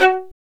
Index of /90_sSampleCDs/Roland L-CD702/VOL-1/STR_Violin 1-3vb/STR_Vln3 _ marc